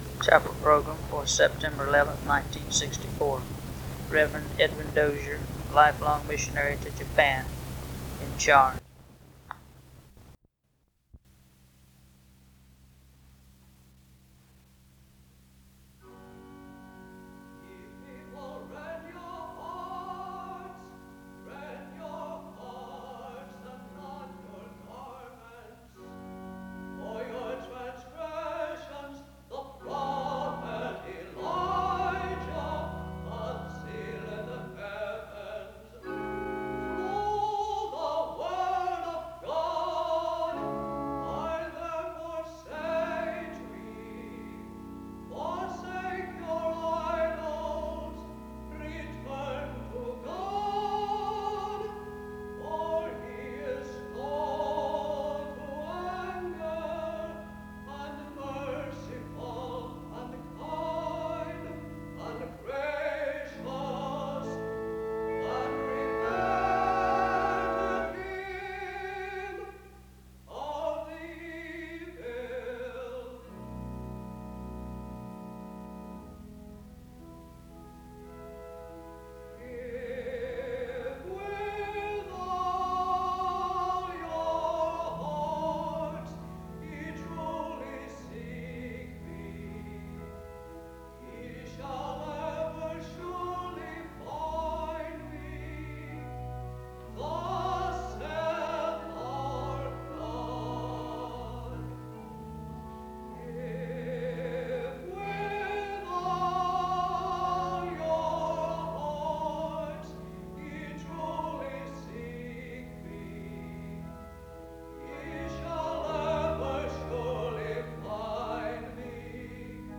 The service begins with special music from 0:16-4:08. There is a prayer from 4:09-6:26. The chapel sings the hymn, “Oh Zion Haste” from 6:28-11:12....
SEBTS Chapel and Special Event Recordings SEBTS Chapel and Special Event Recordings